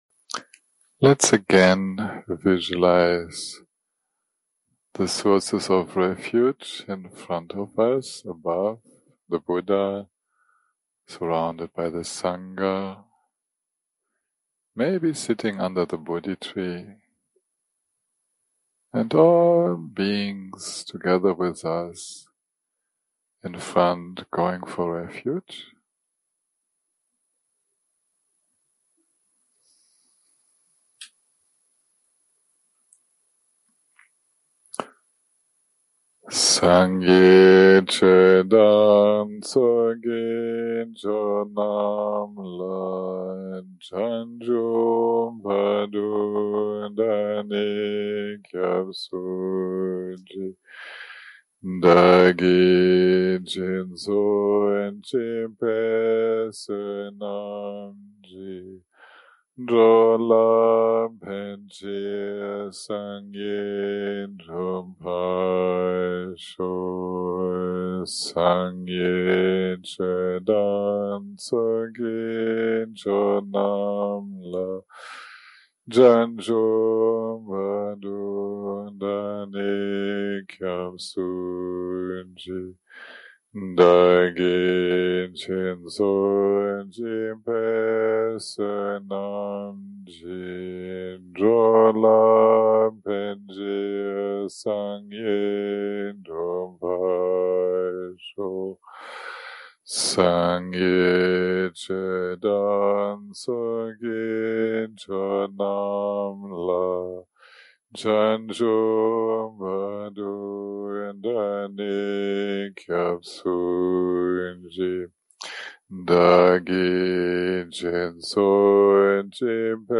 day 8 - recording 27 - Morning - Dharma Talk + Meditation - Preparing for Rfuge Ceremony + Nature of the Mind Meditation
Dharma type: Dharma Talks